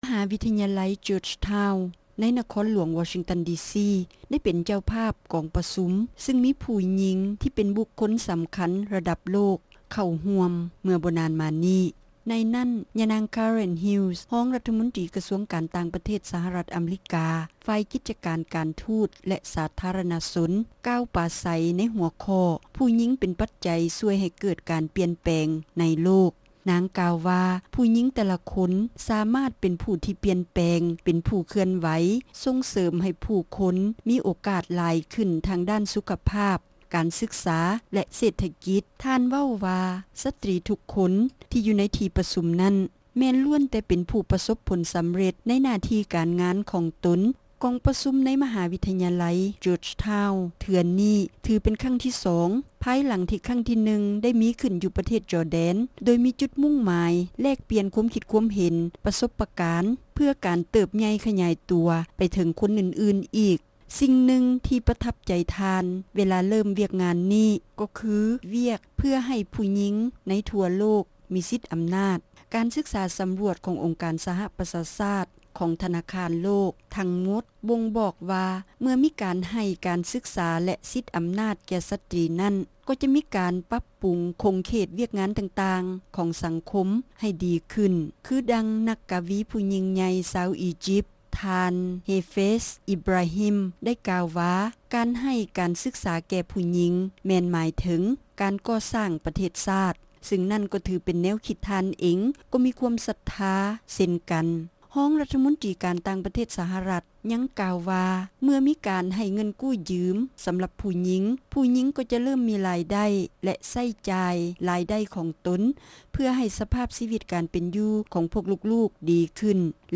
ຄໍາຖະແຫລງ ຂອງ ຮອງ ຣັດຖະມົນຕຣີ ການຕ່າງປະເທດ ສະຫະຣັດ ຝ່າຍກິດຈະການ ການທຸດ-ສາທາຣະນະຊົນ